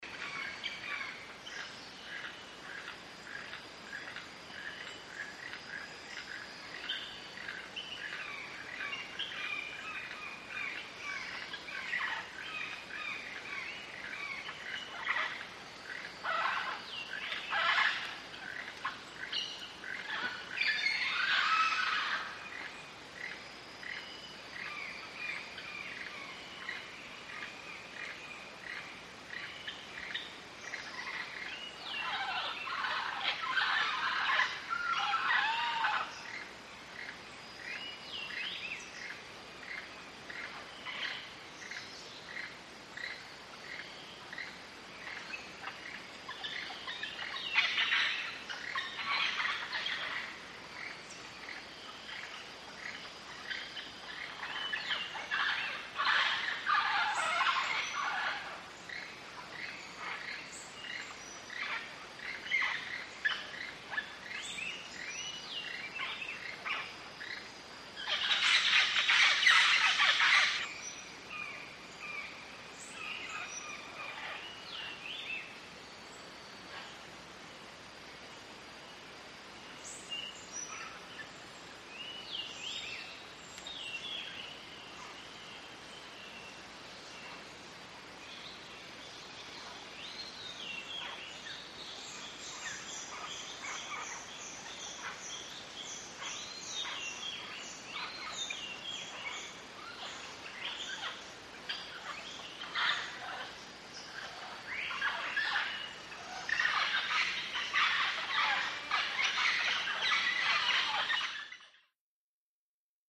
Guatemalan jungle with green parrots & other birds,Tikal